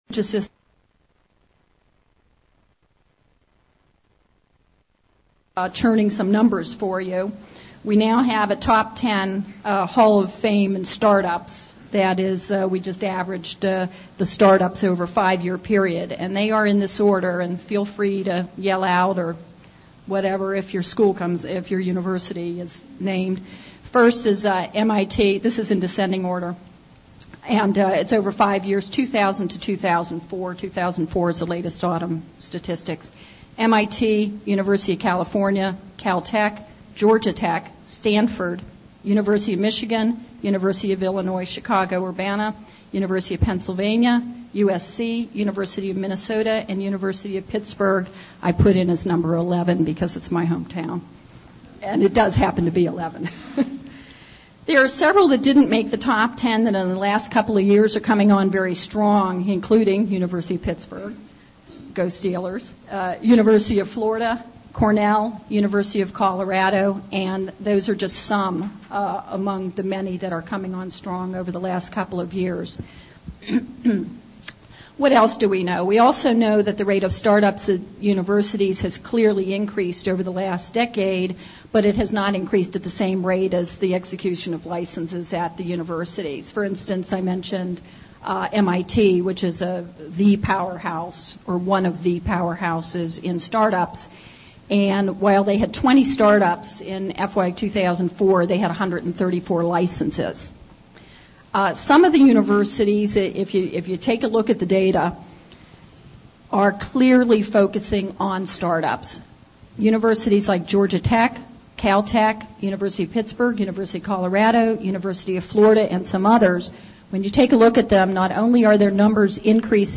University Startups (Luncheon Speech, NCET2 Nat. Conf., Oct. 2006) (AUDIO)